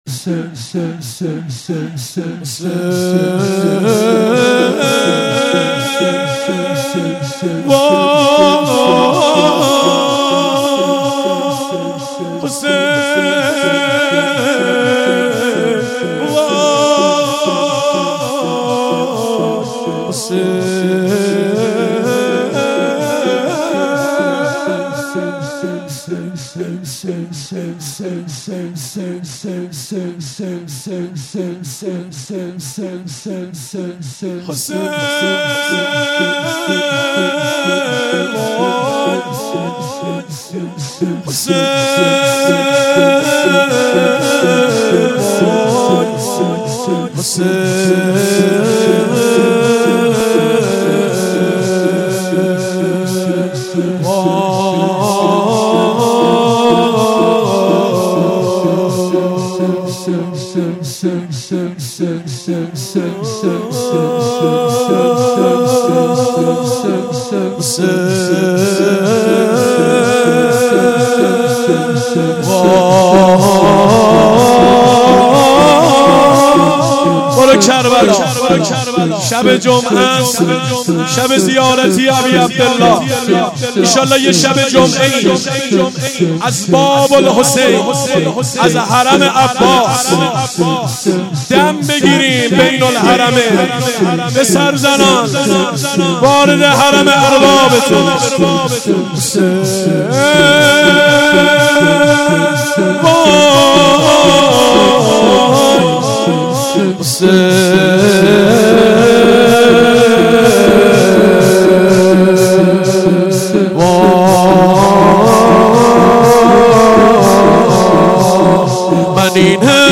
شور2